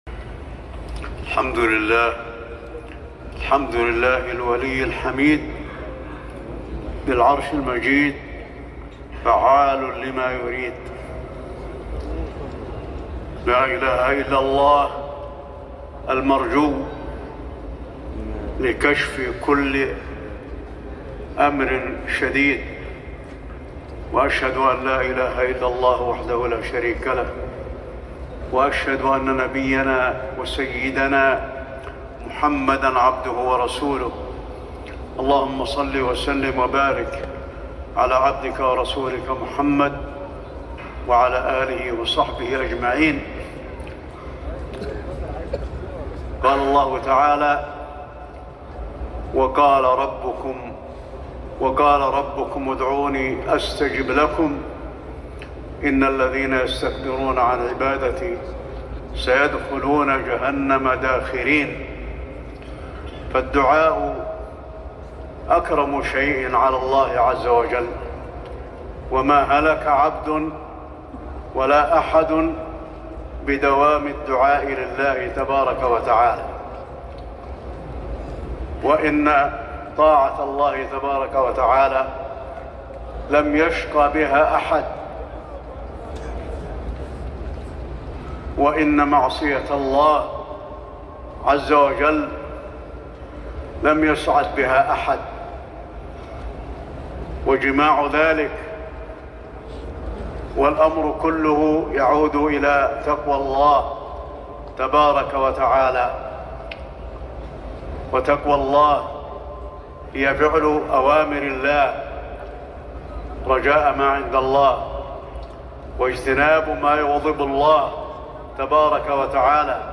كلمة تذكيرية للشيخ علي الحذيفي للتذكير بصلاة الاستسقاء بعد صلاة العشاء 25 جمادى الأولى 1446هـ > كلمات أئمة الحرم النبوي 🕌 > المزيد - تلاوات الحرمين